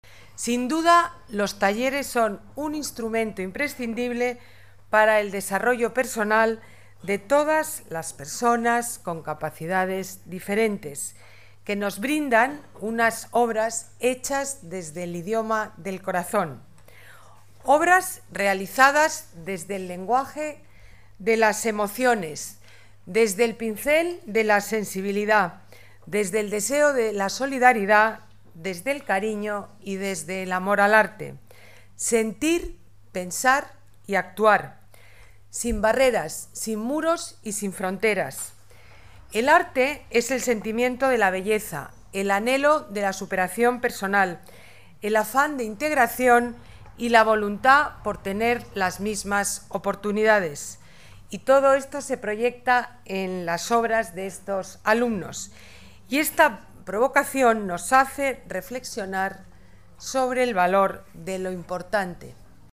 Botella inaugura la muestra organizada por la Fundación Claves de Arte y Madrid Movilidad con obras de personas con discapacidades diferentes
Nueva ventana:Palabras de la alcaldesa